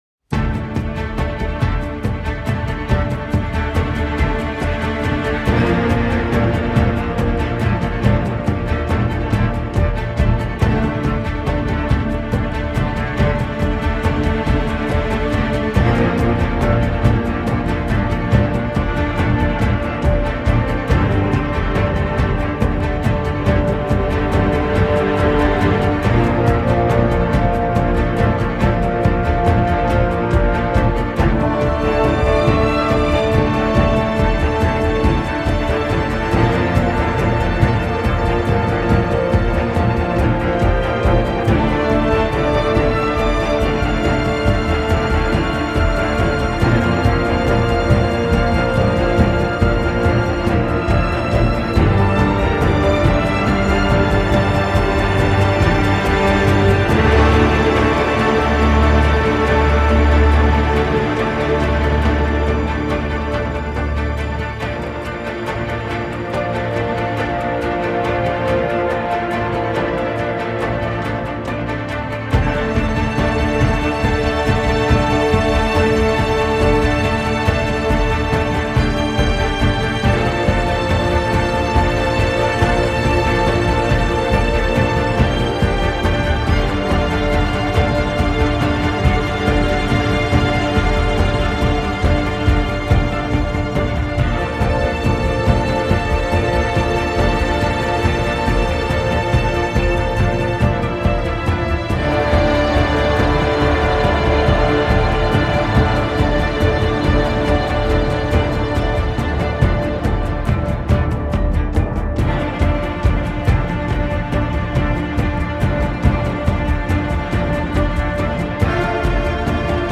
trémolos de cordes, cuivres grondants et percussions
violons pathétiques au ralenti et voix féminine
celle-ci est juste terne et tristounette
extrêmement sombre et dramatique, quasiment sans respiration